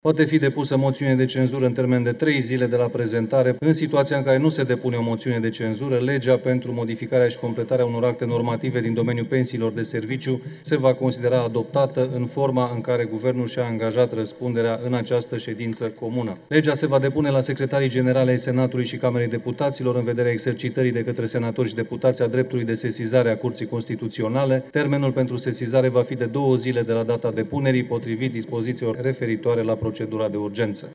De această dată, CSM a dat un aviz negativ proiectului de lege – a arătat premierul, în plenul comun al parlamentului.